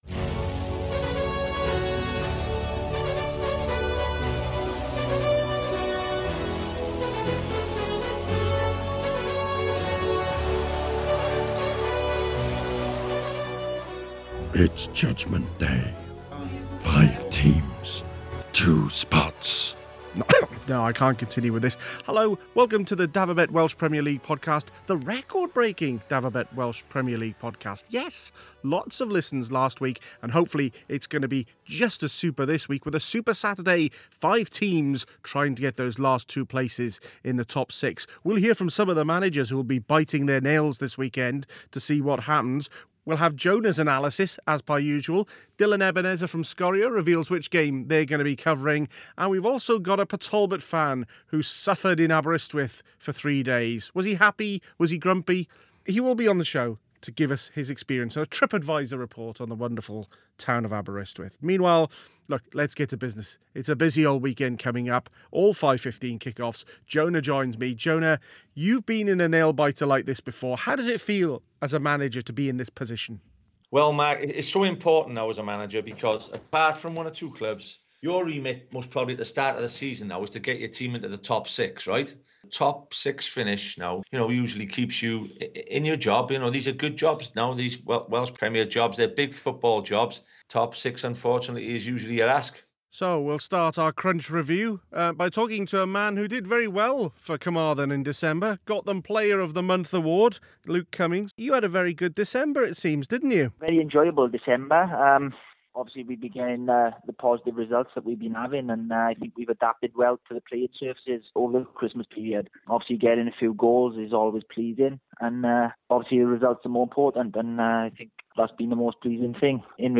Five teams will fight for two spots before the league splits after this weekend... Who will make it? We hear from managers and players in the mix